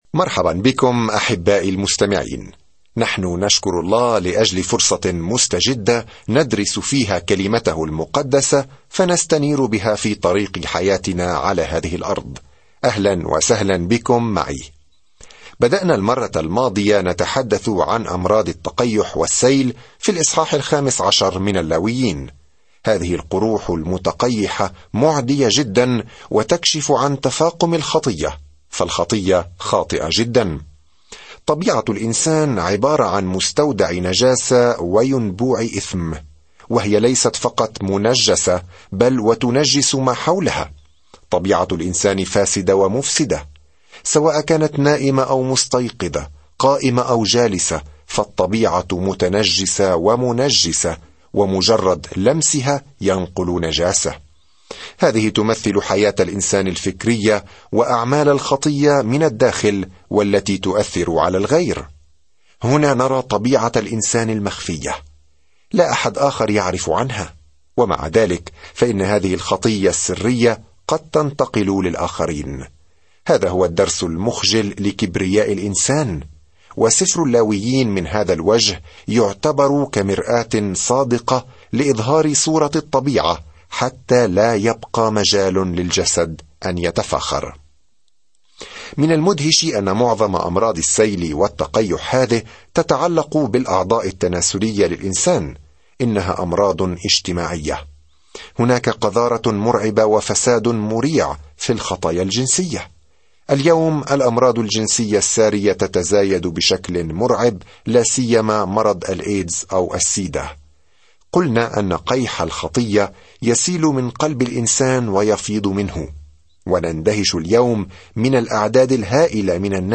في العبادة والتضحية والتبجيل، يجيب سفر اللاويين على هذا السؤال بالنسبة لإسرائيل القديمة. سافر يوميًا عبر سفر اللاويين وأنت تستمع إلى الدراسة الصوتية وتقرأ آيات مختارة من كلمة الله.